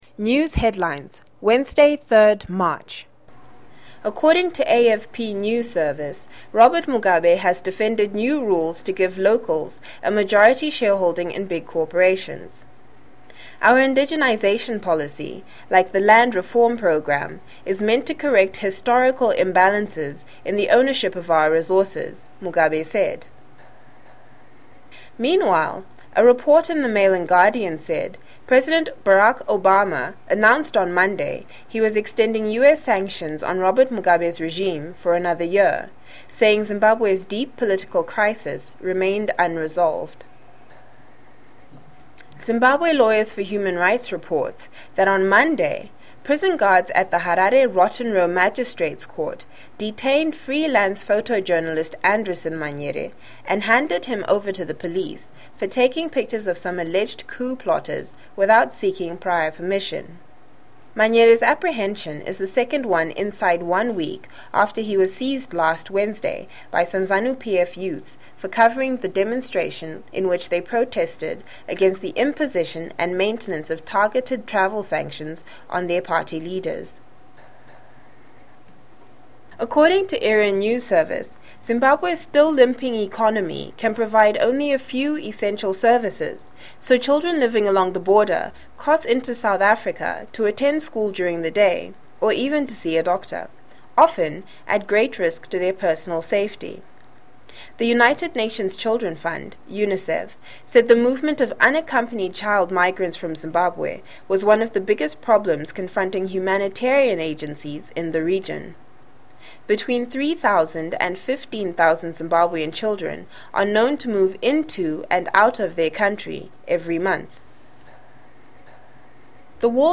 News headlines     shares a round up of local, regional and international news daily